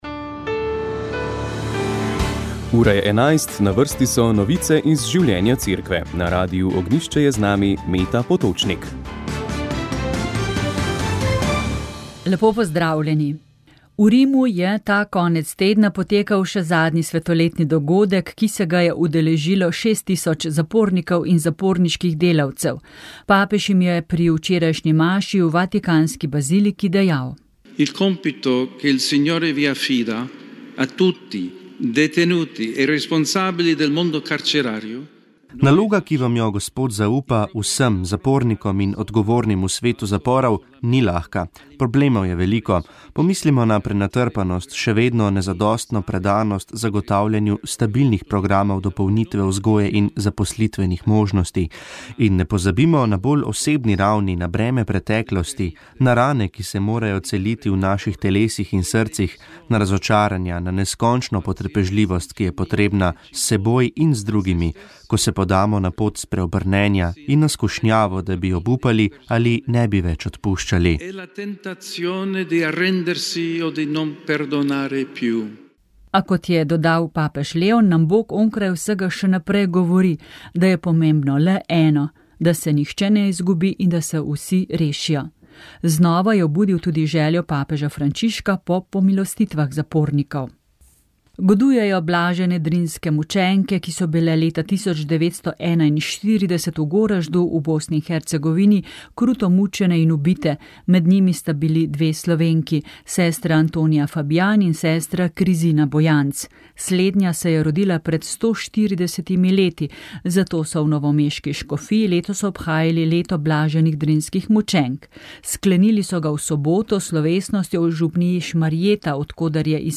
Informativne oddaje